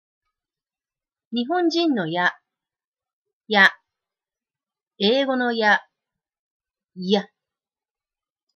英語のYは、舌をもっとギュッと盛り上げます。
日本人の「や」と英語の「Ya」
最初のねばりが、違うのがわかりますね？